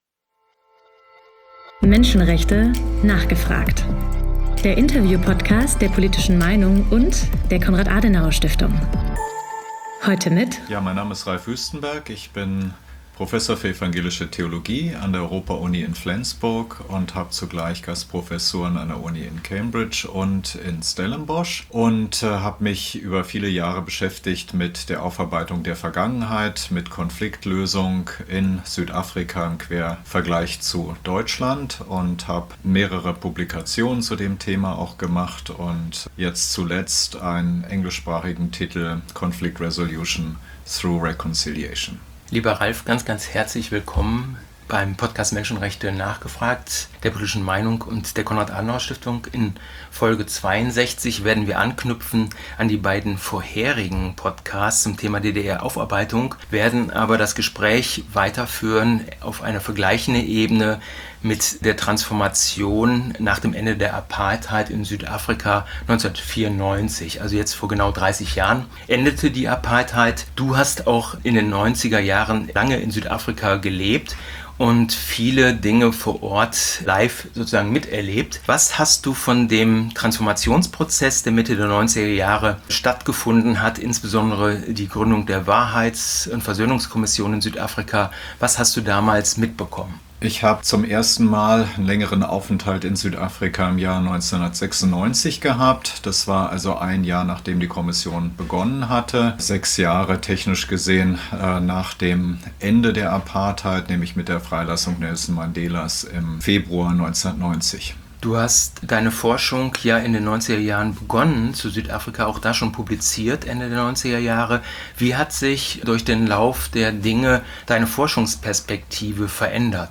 Menschenrechte: nachgefragt! - Der Interview-Podcast rund ums Thema Menschenrechte